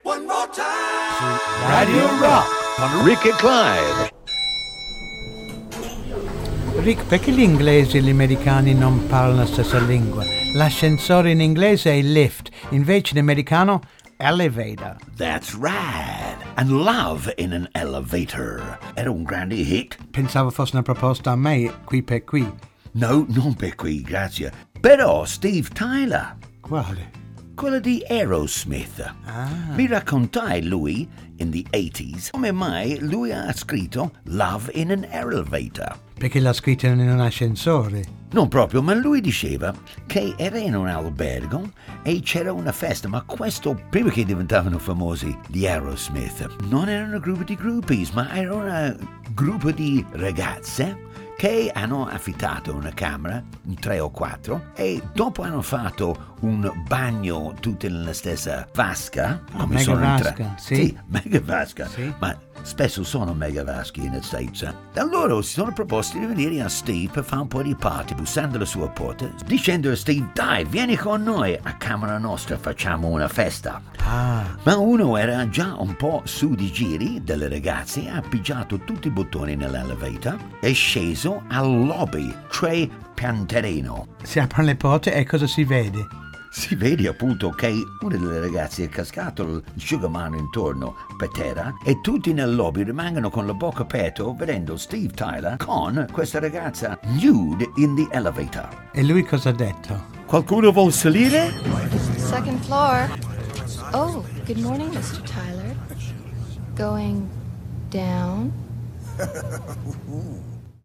Voci: Rick Hutton, Clive Griffiths.